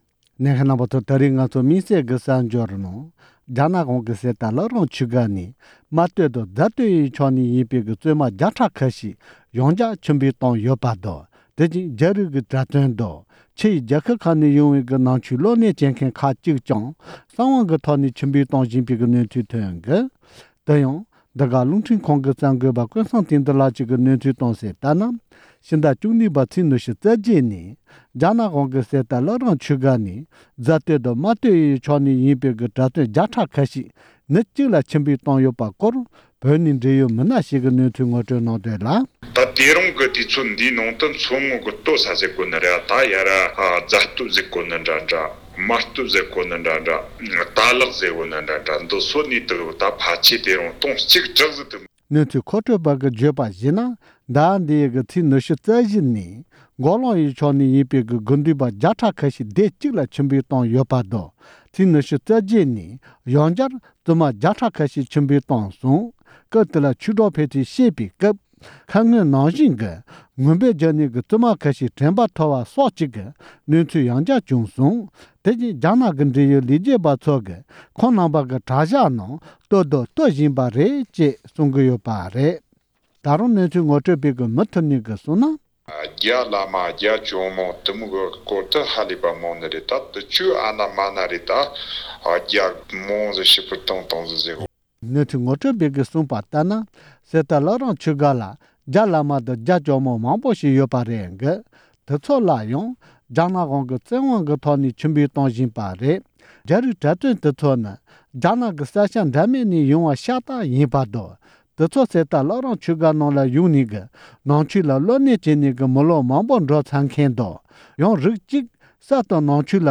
མགོ་ལོག་རྨ་སྟོད་དང་རྫ་སྟོད་ནས་ཡིན་པའི་བཙུན་མ་བླ་རུང་སྒར་ནས་ཕྱིར་ཕུད་བཏང་བ། གཉིས་པ། སྒྲ་ལྡན་གསར་འགྱུར།